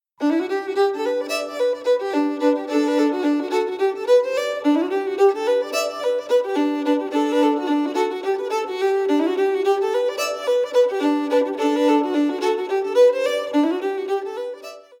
1. Melody recorded at 068 bpm
2. Melody recorded at 088 bpm
3. Melody recorded at 108 bpm